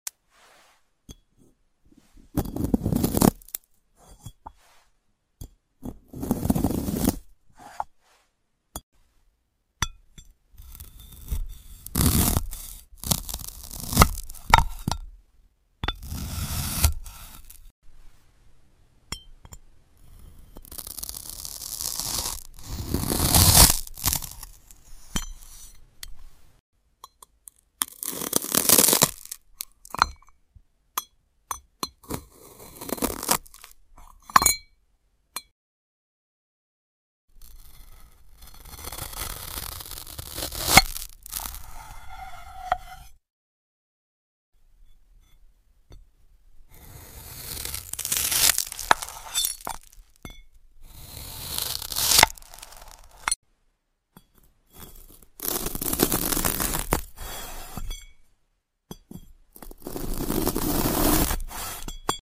Apple Cutting into Slice sound effects free download